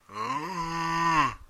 生物的声音 " 亡灵的呻吟 4
描述：模仿僵尸/亡灵呻吟。
Tag: 怪物 僵尸 僵尸 恐怖 怪物 不死